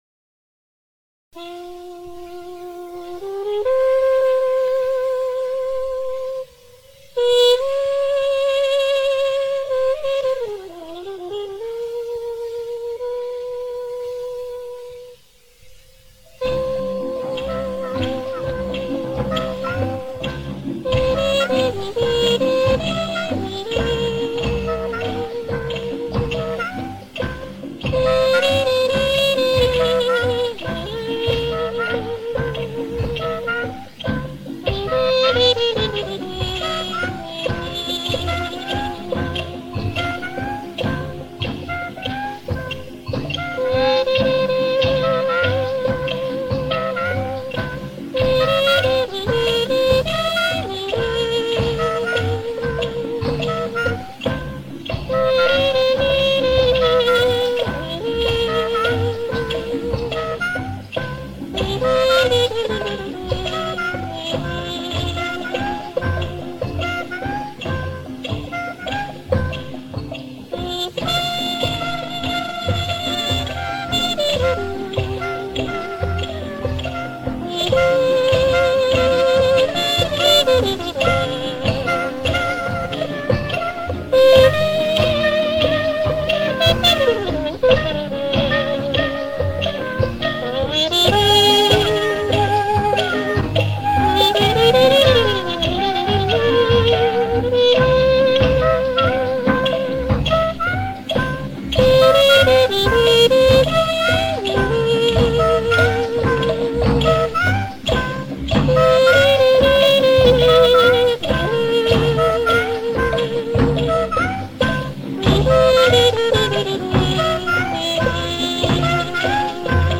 Соло на трубе.